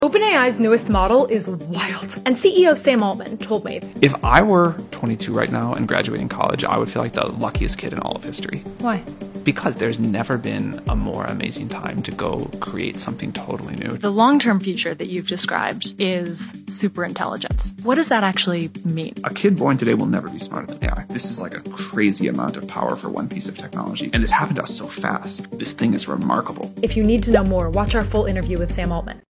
I interviewed CEO Sam Altman about what it can do, and what comes next… Big picture, they’re trying to build a “superintelligence” that could far exceed humans in almost every field.